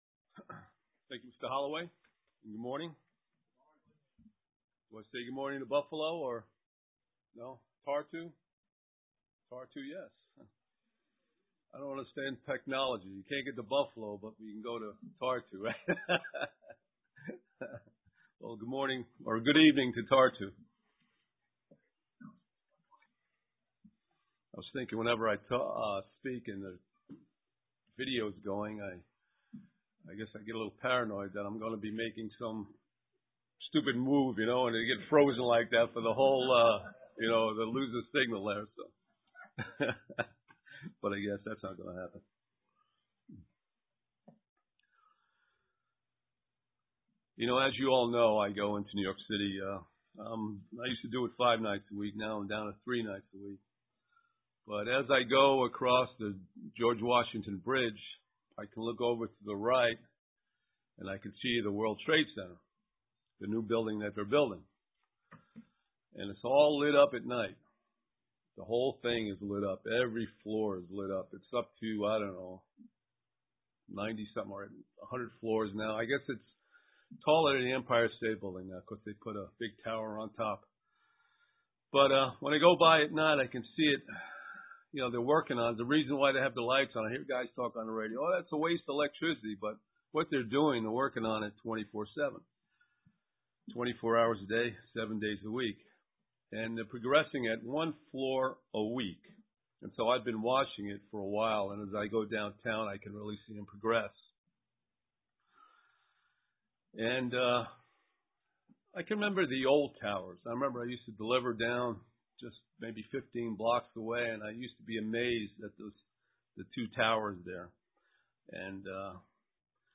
UCG Sermon Studying the bible?
Given in Elmira, NY